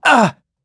Siegfried-Vox_Damage_01_b.wav